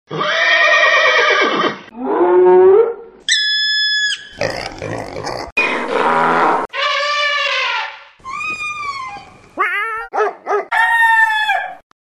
Du hörst jetzt 10 verschiedene Tiere ¿ findest du heraus, welche das sind?
Pferd
Hyäne
Specht
Schwein
Esel
Elefant
Wal
Katze
Hund
Hahn